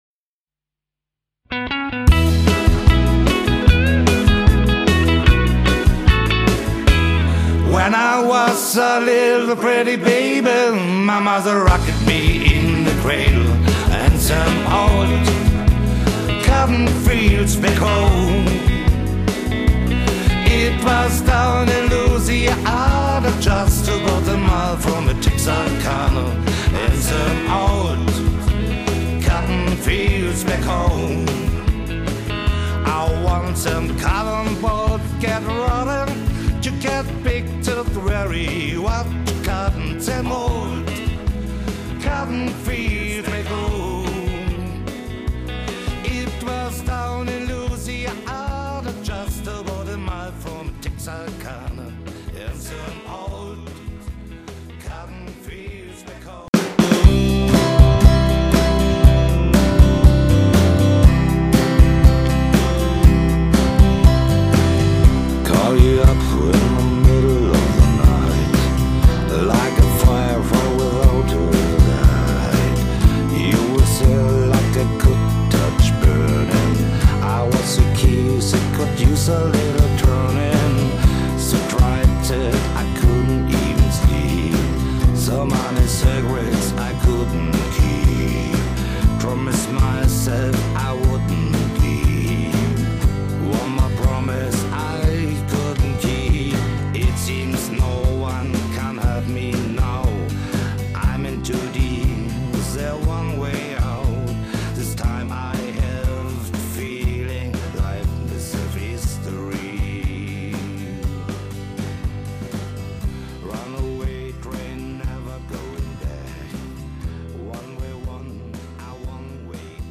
(Ost-Rock sowie International)
• Alleinunterhalter
• Sänger/in